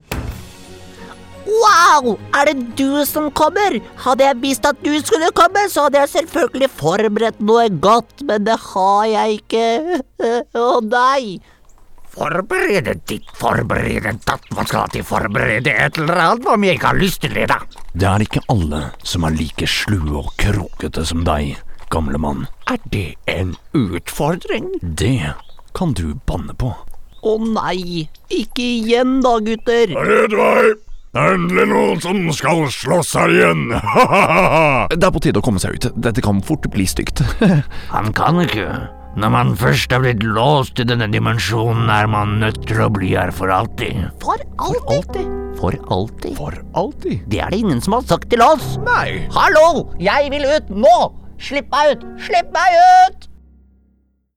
uma voz barítono norueguesa, profunda e acolhedora
Videogames
Mic: Shure SM7B + Trtion Audio Fethead Filter
BarítonoProfundoBaixo